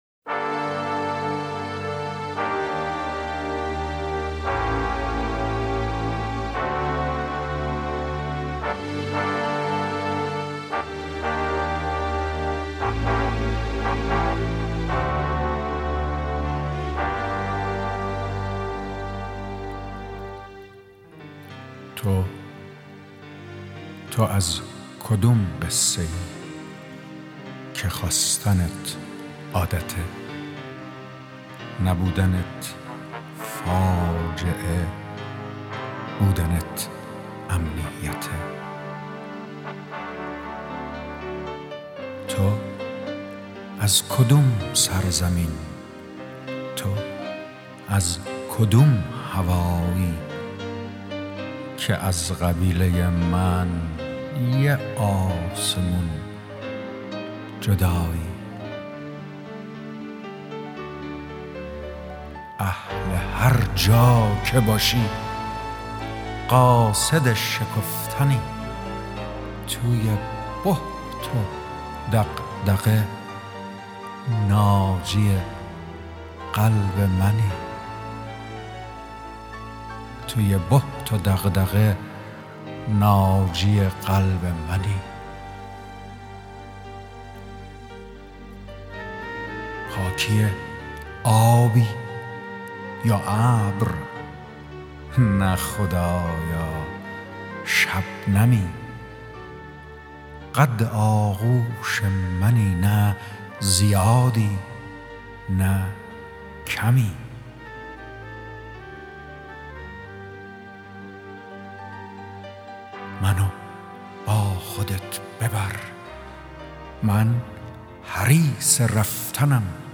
دانلود دکلمه همسفر با صدای ایرج جنتی عطایی با متن دکلمه
گوینده :   [ایرج جنتی عطایی]
آهنگساز :   واروژان